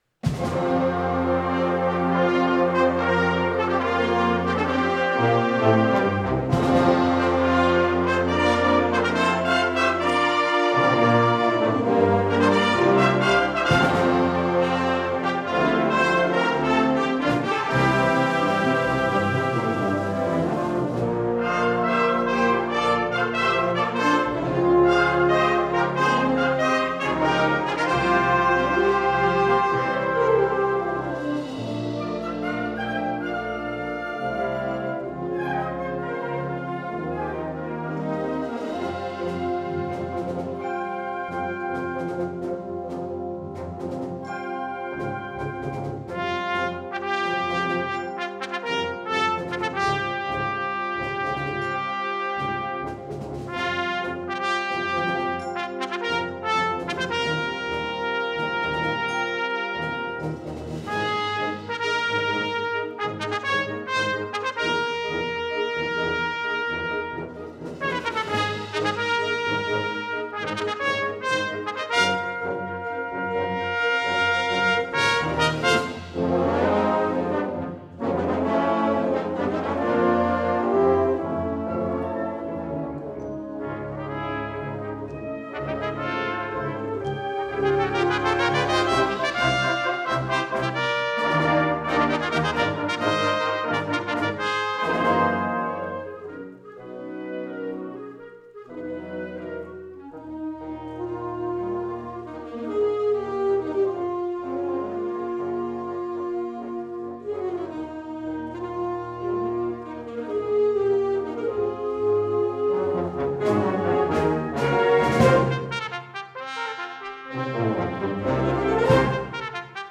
The piece was designed specifically for two B-flat trumpets.